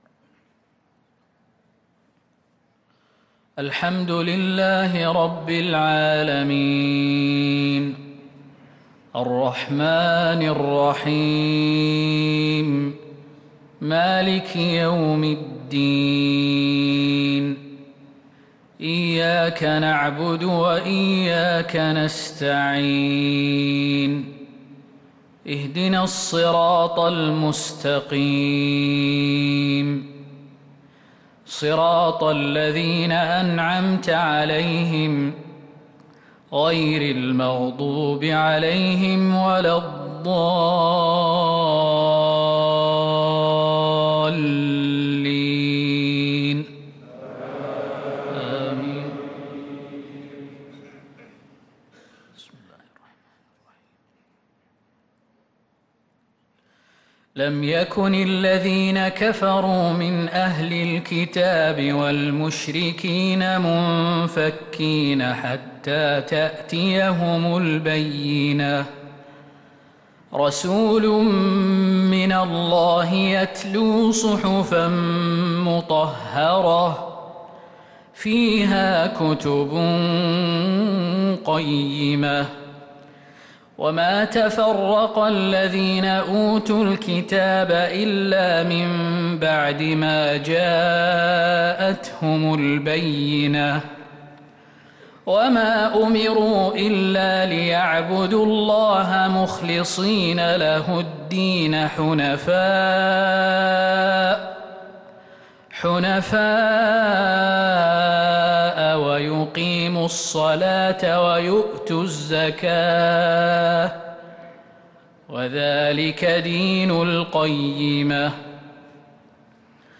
عشاء الجمعة 6-2-1444هـ سورتي البينة و الزلزلة | Isha prayer Surat al-Bayyinah & az-Zalzala 2-9-2022 > 1444 🕌 > الفروض - تلاوات الحرمين